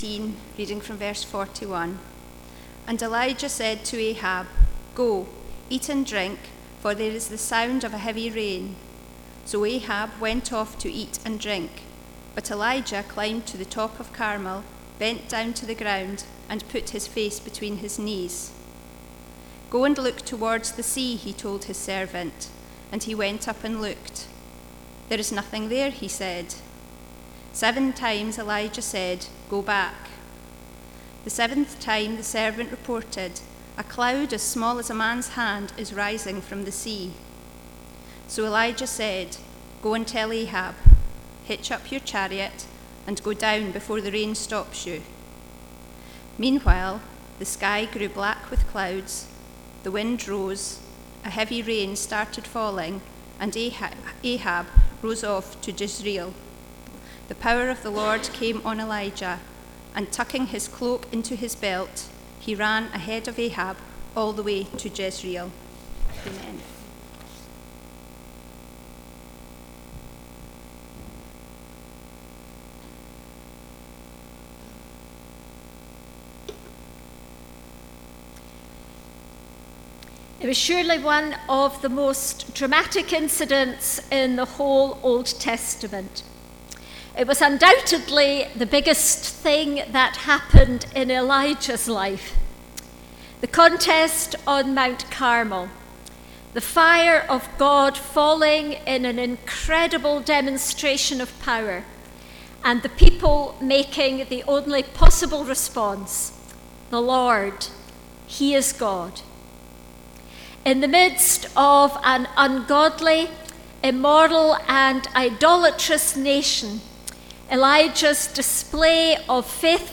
Elijah Passage: 1 Kings 18:41-46 Service Type: Sunday Morning « The Lord